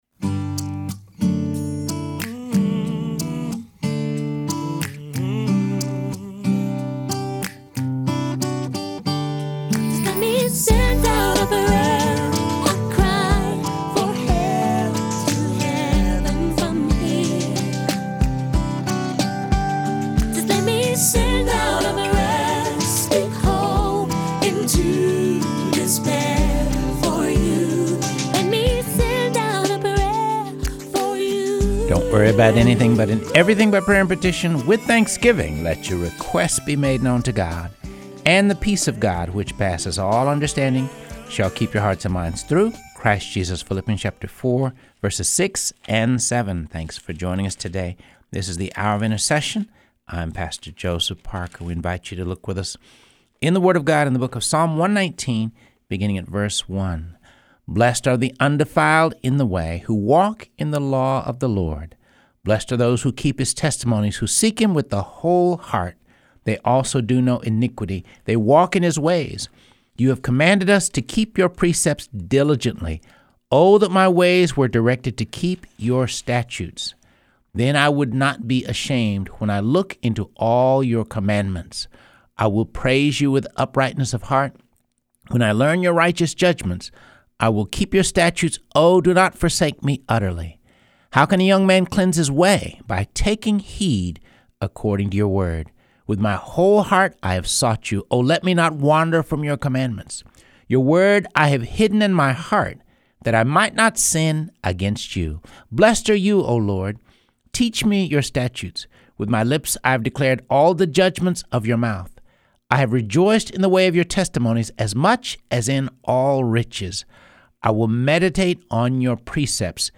reads Key Inspirational Passages in God’s Word.